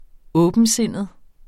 Udtale [ -ˌsenˀəð ]